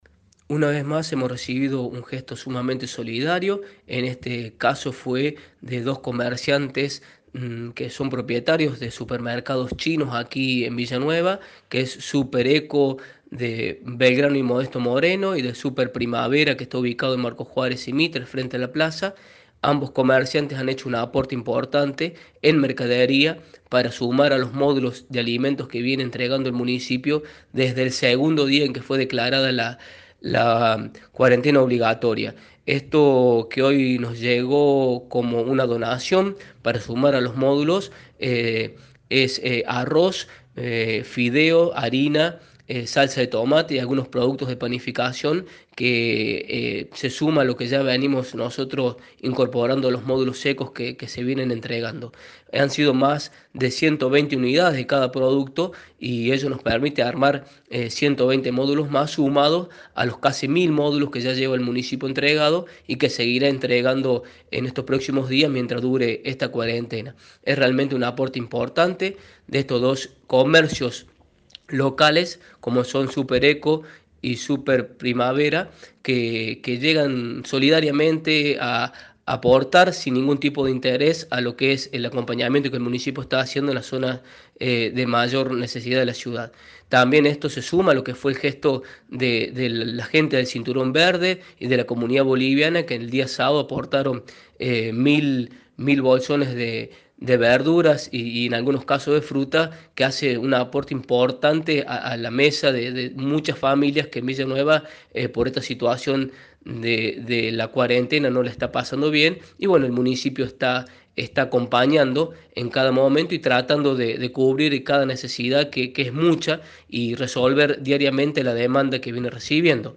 Mauro Bizzarri, secretario de Relaciones Institucionales y Políticas Sociales del municipio, habló con Cadena 3 Villa María.